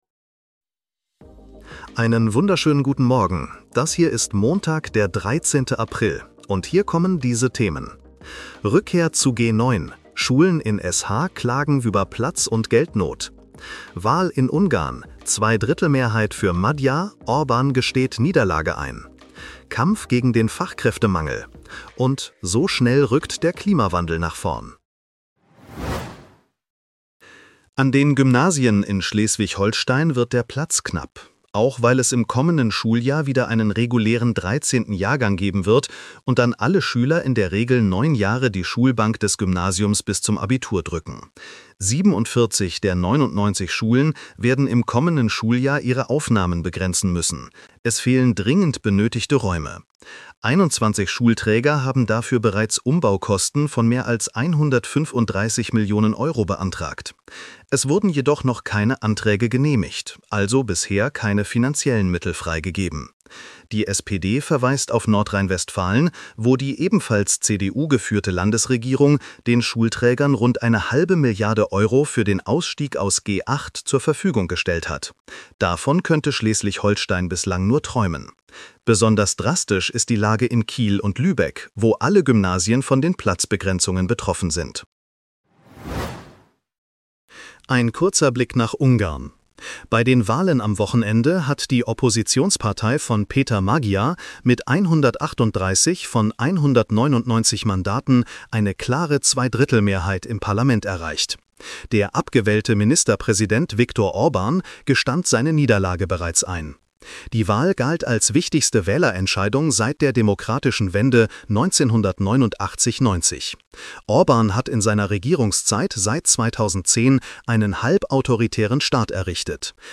Nachrichten-Podcast bekommst Du ab 7:30 Uhr die wichtigsten